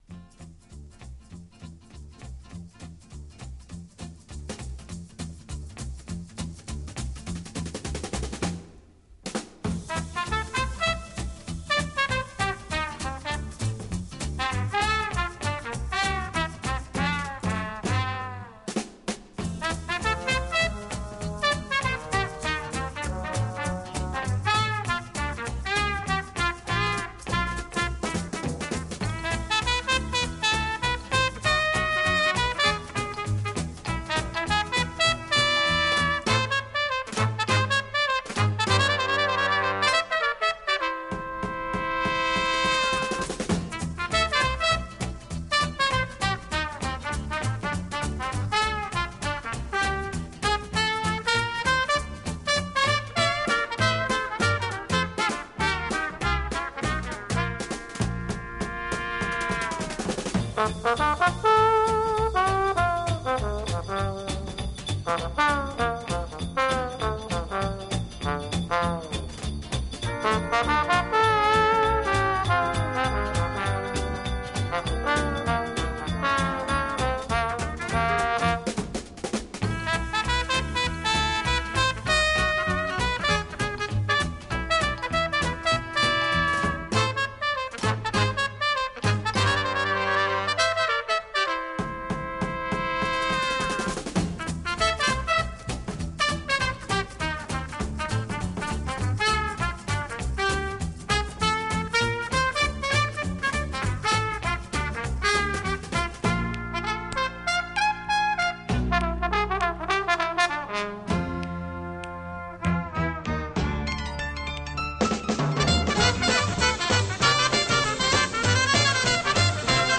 Mexican Brass Style